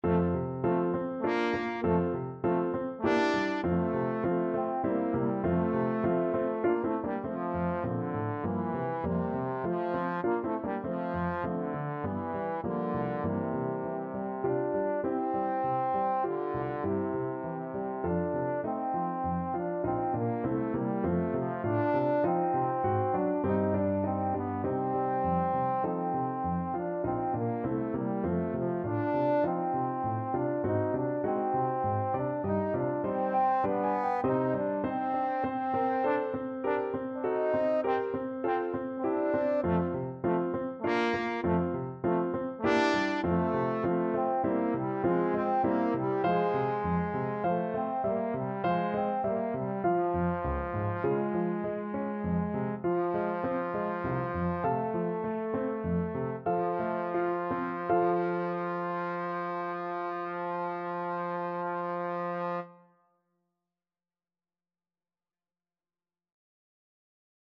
Trombone
F major (Sounding Pitch) (View more F major Music for Trombone )
3/4 (View more 3/4 Music)
~ = 100 Allegretto grazioso (quasi Andantino) (View more music marked Andantino)
C4-Eb5
Classical (View more Classical Trombone Music)
brahms_sym2_3rd_mvt_TBNE.mp3